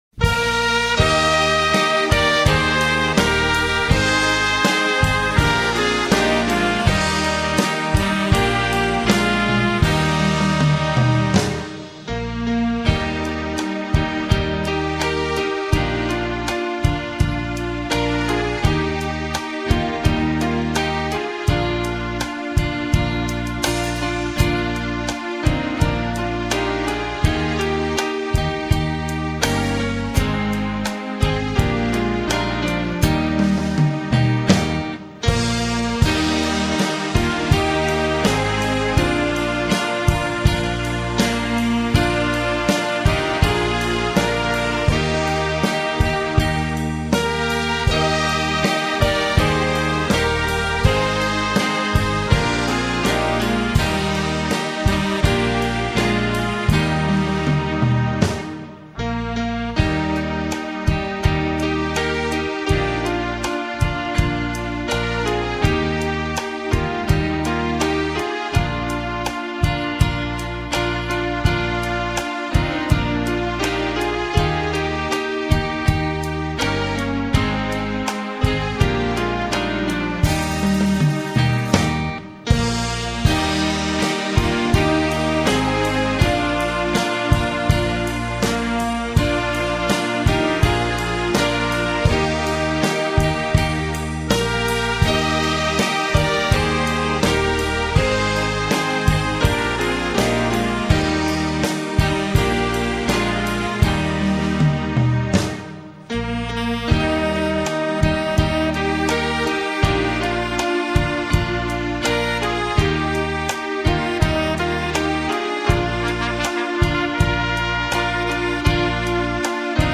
Waldecker Lied (Instrumental)
waldecker_lied_instrumental.mp3